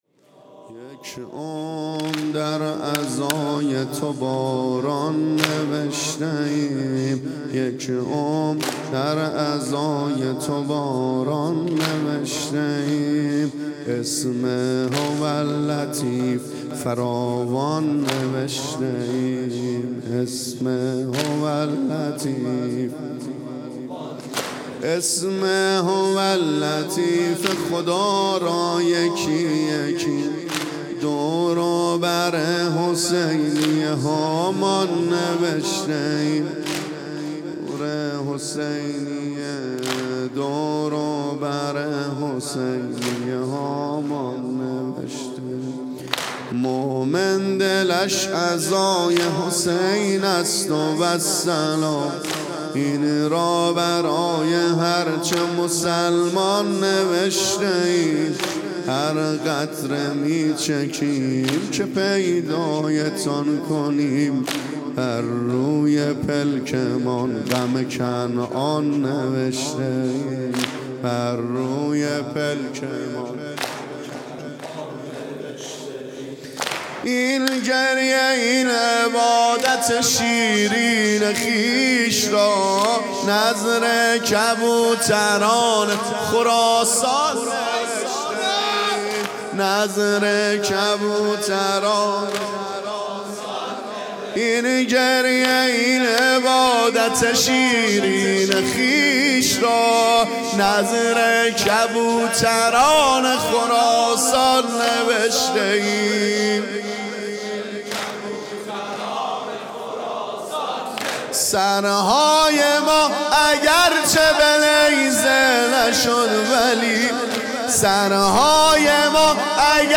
هیئت دانشجویی فاطمیون دانشگاه یزد
یادواره شهدا|۱۹ اردیبهشت ۱۳۹۵